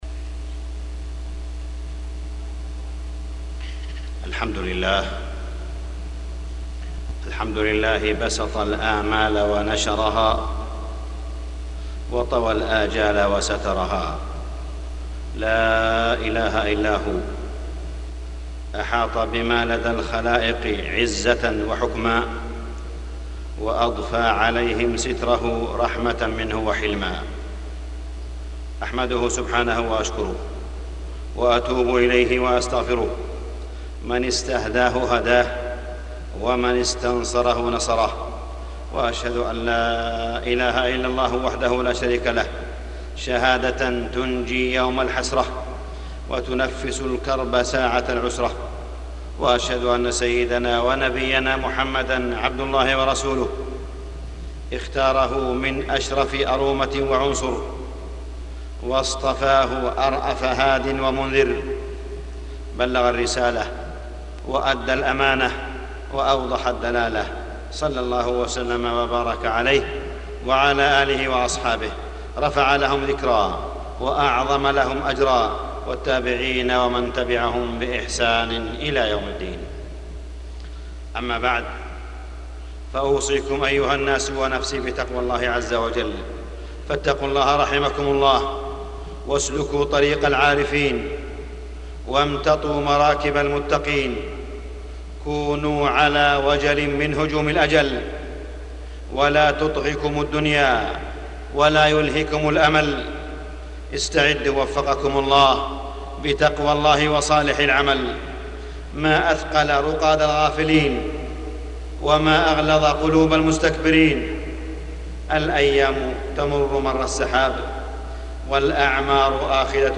تاريخ النشر ٢٣ ربيع الأول ١٤٢٧ هـ المكان: المسجد الحرام الشيخ: معالي الشيخ أ.د. صالح بن عبدالله بن حميد معالي الشيخ أ.د. صالح بن عبدالله بن حميد حرية التعبير